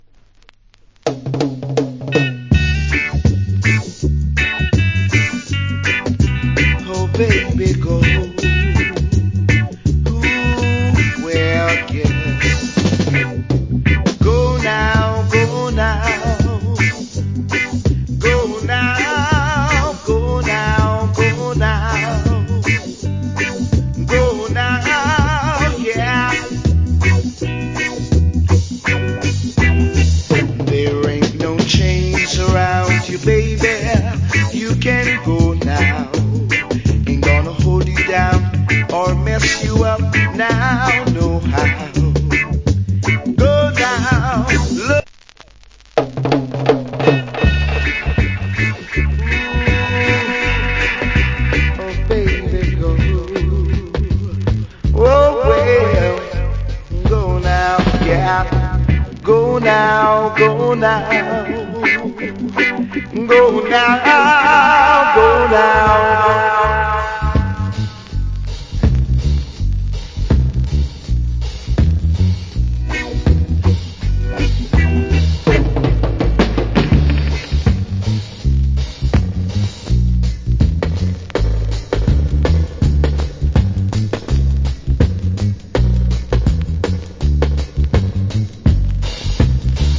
Nice Reggae Vocal.